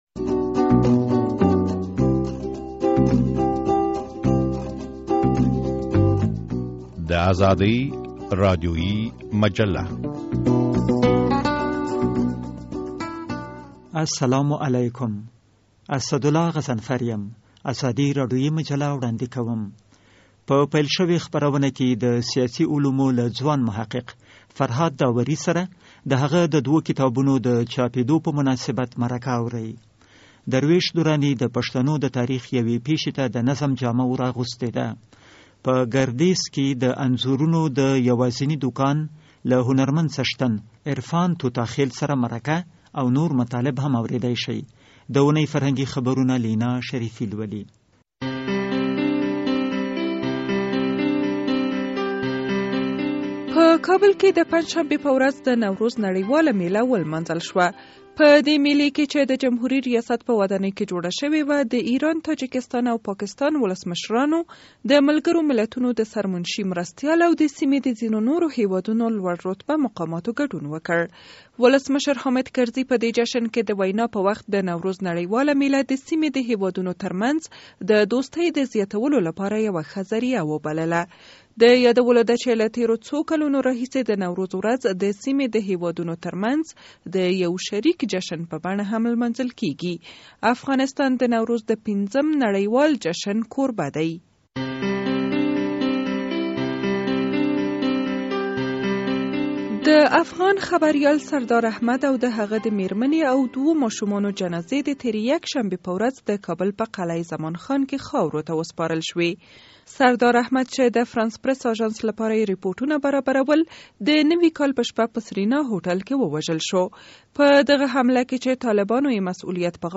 د انځورونو د دکان له څښتن سره مرکه اورئ!